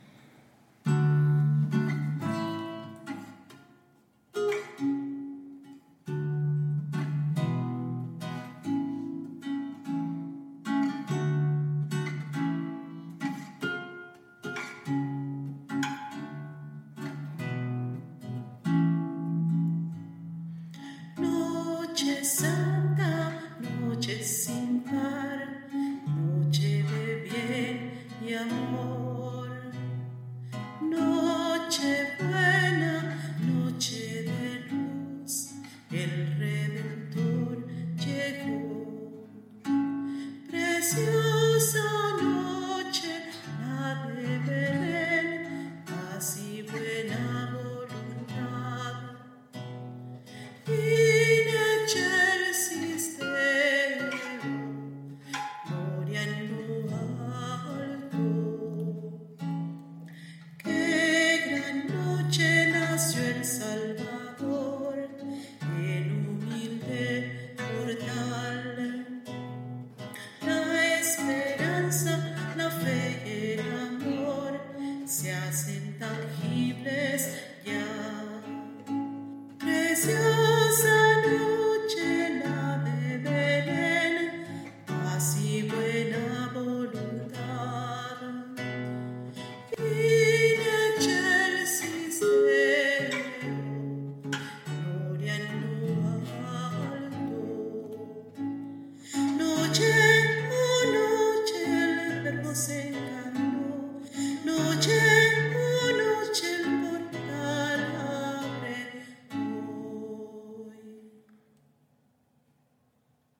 Creado para la liturgia
Audio voz: